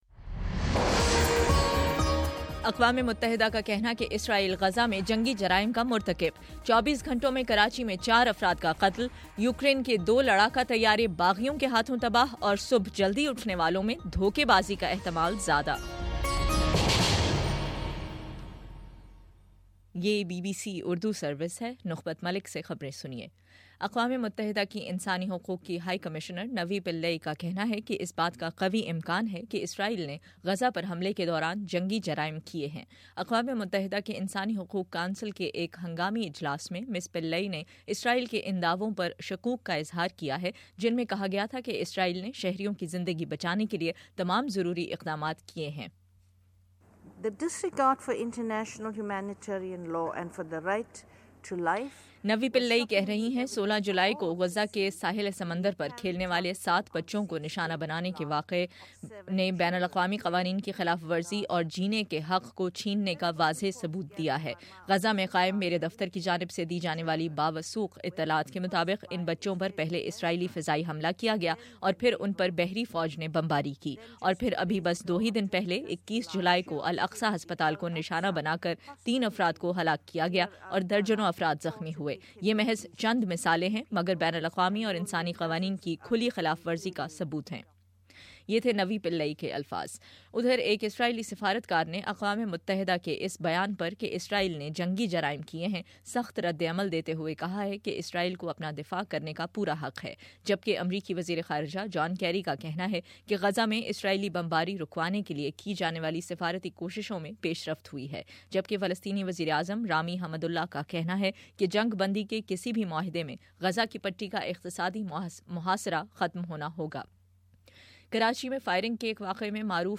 تئیس جولائی: شام 7 بجے کا نیوز بُلیٹن
دس منٹ کا نیوز بُلیٹن روزانہ پاکستانی وقت کے مطابق صبح 9 بجے، شام 6 بجے اور پھر 7 بجے۔